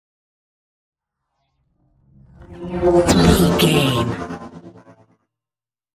Pass by fast vehicle sci fi
Sound Effects
No
futuristic
pass by